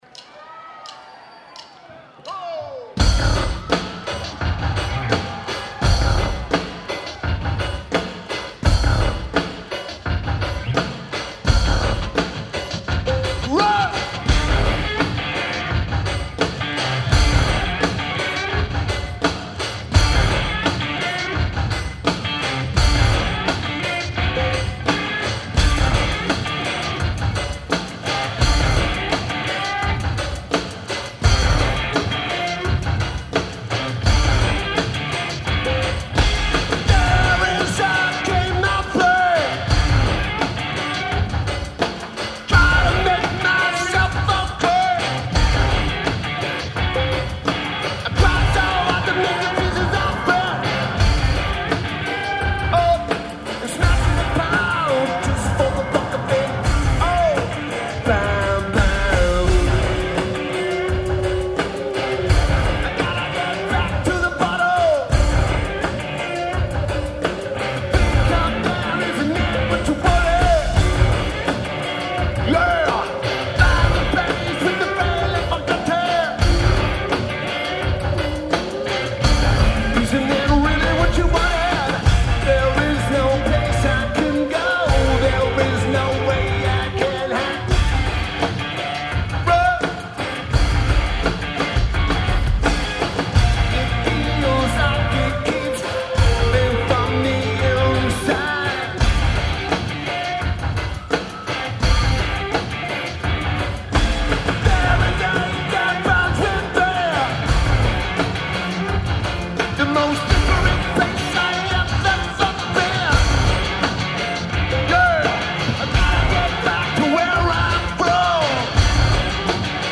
The Palladium
Lineage: Audio - AUD (Schoeps MK41 + Tinybox + Sony PCM-M10)
Taped from the stack.